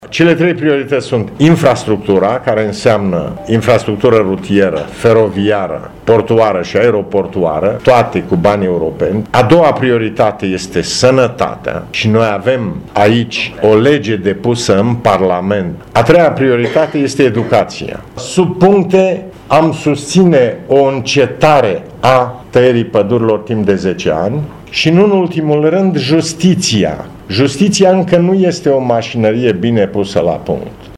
Aflat în vizită la Școala de Vară a Tineretului PMP, de la Brașov, președintele formațiunii politice, Traian Băsescu, a subliniat, cu prilejul unei conferințe de presă, importanța recentei fuziuni cu UNPR: